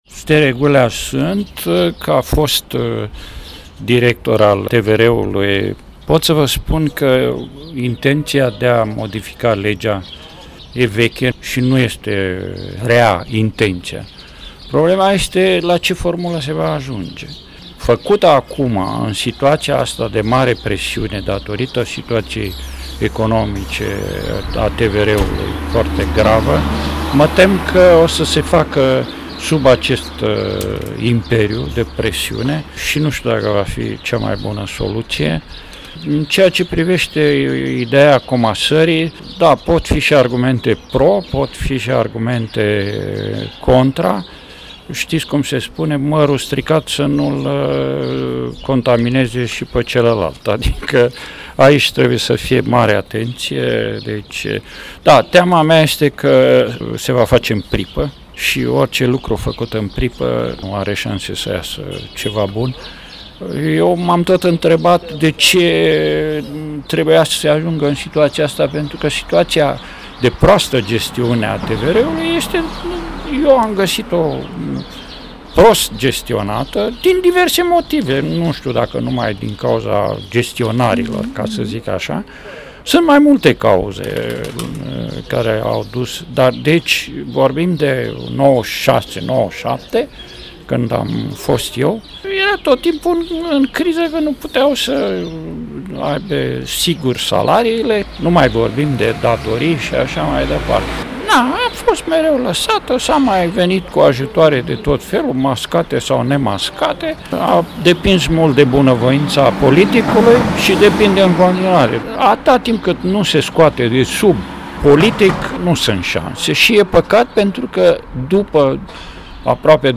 Regizorul Stere Gulea vorbeste despre sansele de redresare pe care le are televiziunea publica prin modificarea legii 41/1994.
Un interviu